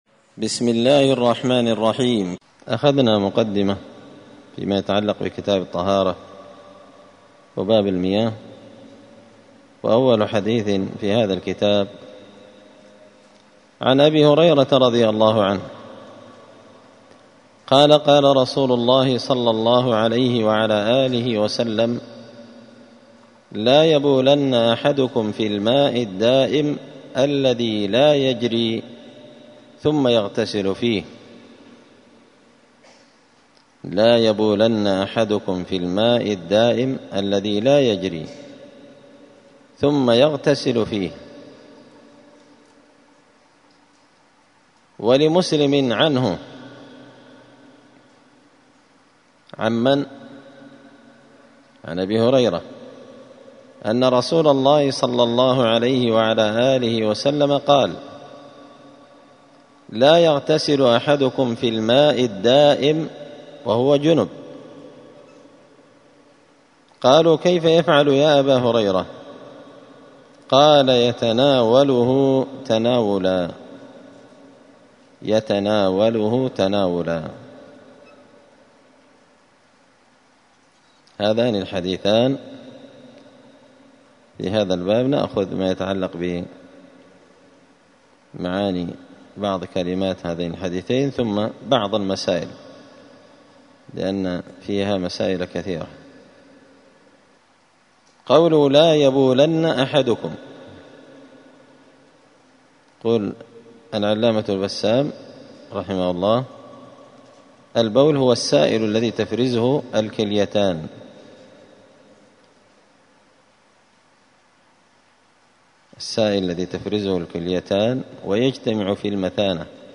دار الحديث السلفية بمسجد الفرقان بقشن المهرة اليمن
*الدرس الثالث (3) {باب المياه حكم البول في الماء الدائم…}*